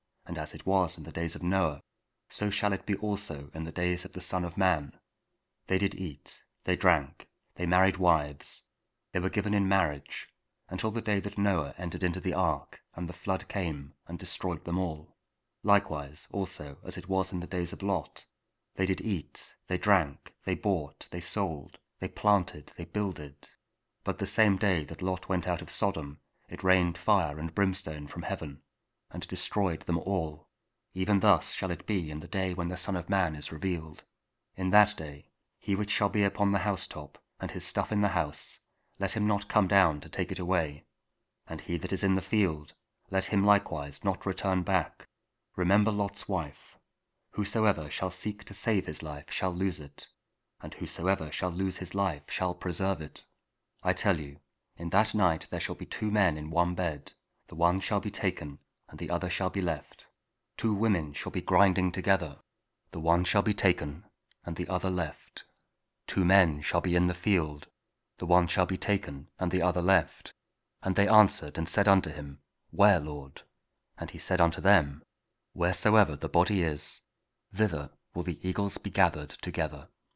Luke 17: 26-37 – Week 32 Ordinary Time, Friday (King James Audio Bible KJV, Spoken Word)